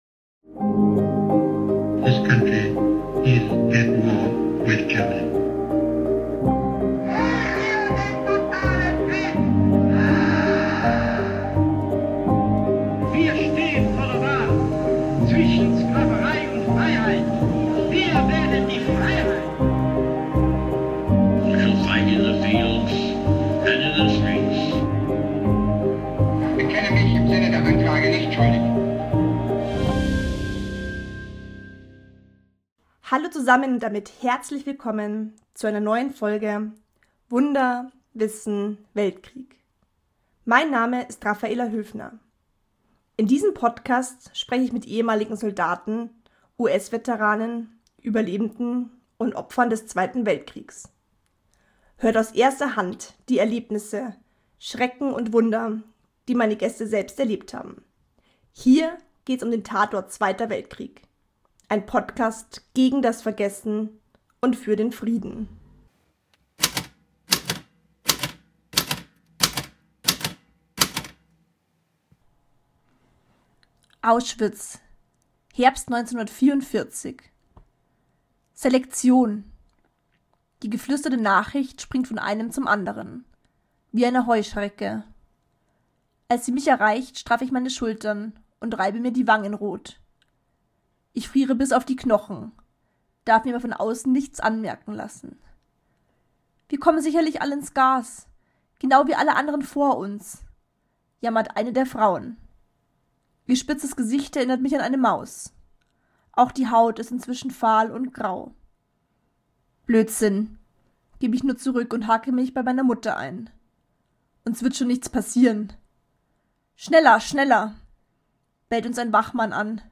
Hier hört ihr aus erster Hand all die Erlebnisse, die Schrecken und Wunder, die die Interviewgäste selbst erlebt haben.